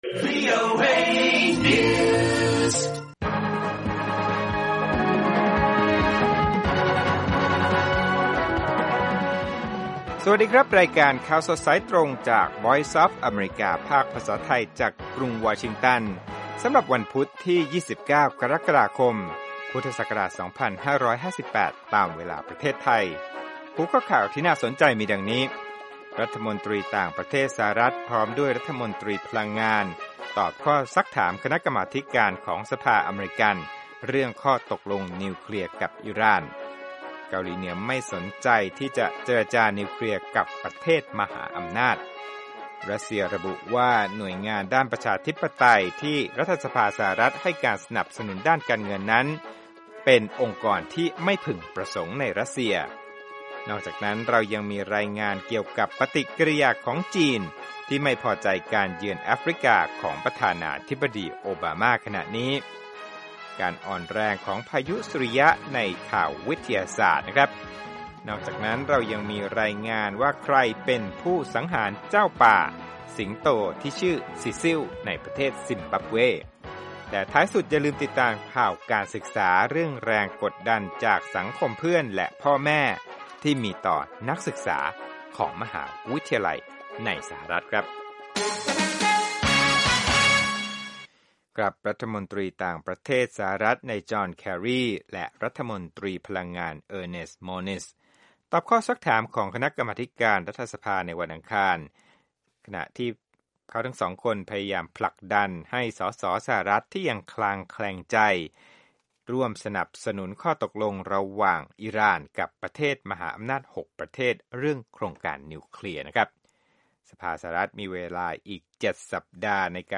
ข่าวสดสายตรงจากวีโอเอ ภาคภาษาไทย 6:30 – 7:00 น.วันพุธ 29 ก.ค. 2558